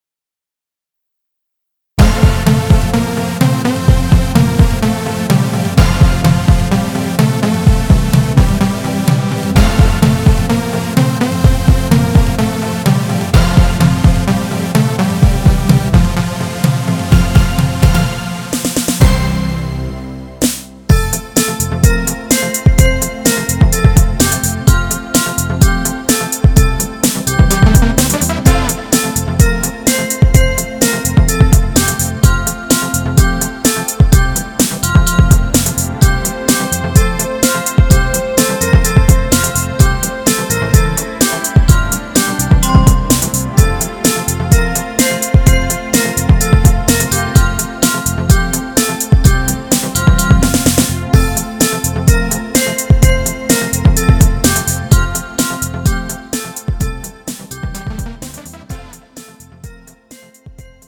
음정 F 키
장르 가요 구분 Pro MR